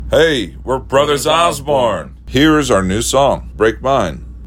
LINER Brothers Osborne (Break Mine) 2